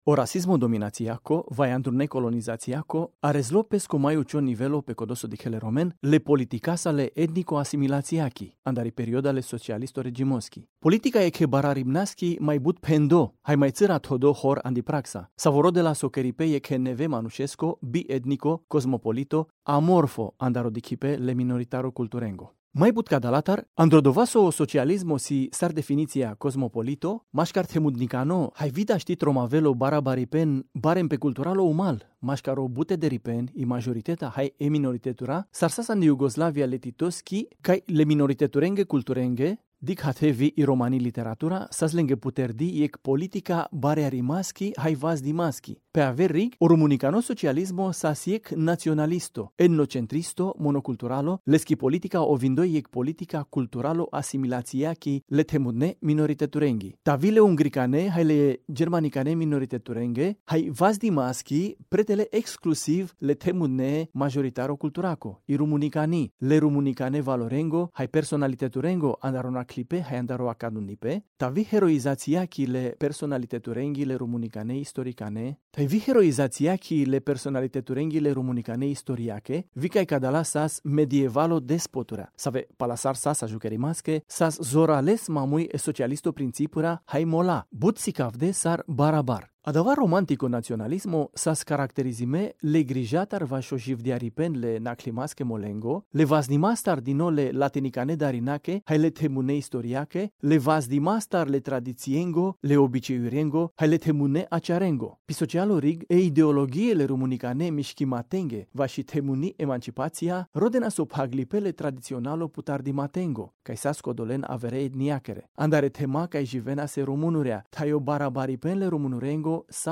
Audiobook-RR-Sp-5.mp3